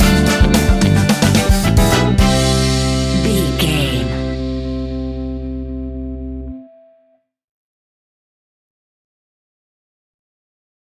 Ionian/Major
latin
salsa